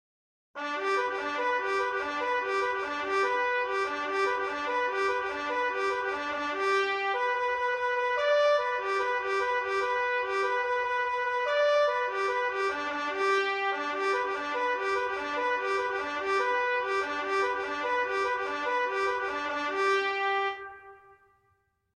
Traditional : Reveille (US) - a military bugle call: Sheet Music
Traditional Bugle Call: Reveille (US)
Reveille (US bugle call) - MP3 & Midi files